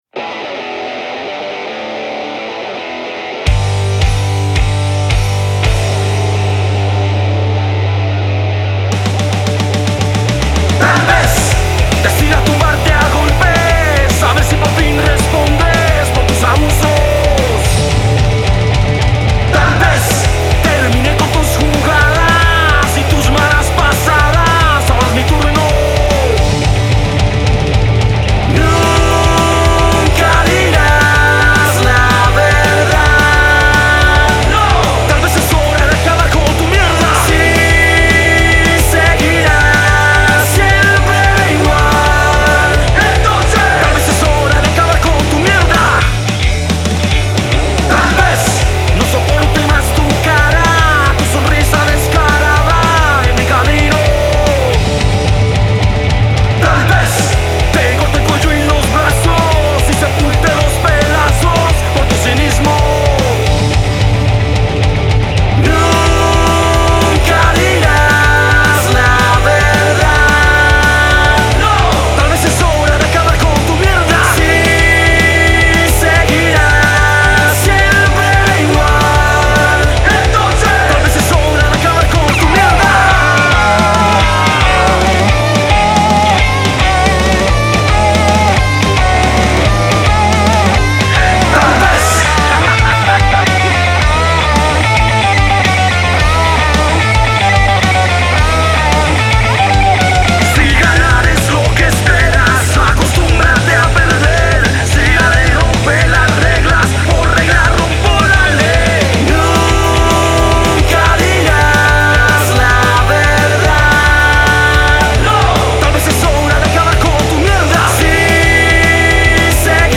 Rock Alternativo y Grunge
Punk rock